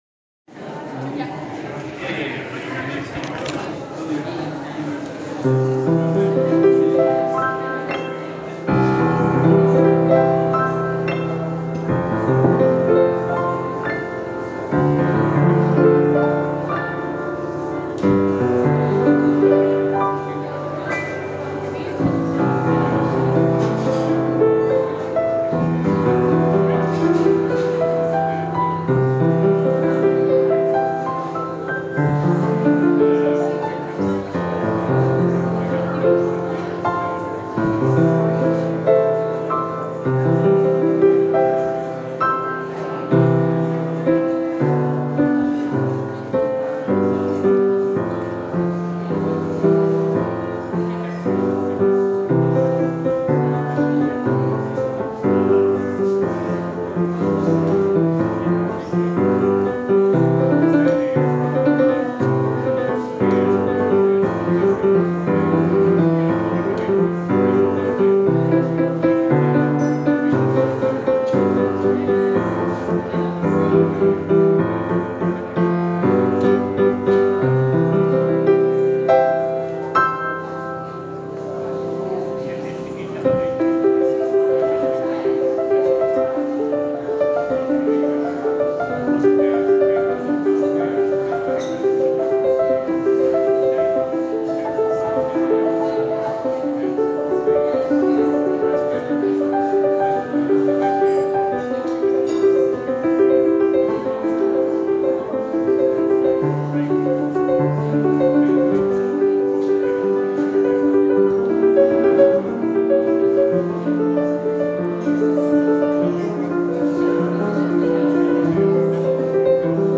Appledore Flower Festival with piano and songs
Hello and Welcome to Appledore Flower Festival 2025 Theme Hobbies, the flowers are beyond exquisite and the sun is shining, there’s chocolate cake, an art exhibition, book stall and the pianist is just warming up
Summertime sounds like a strangled cat
half way the singer arrives
my last jazz pastiche which goes rather well
FF-piano.mp3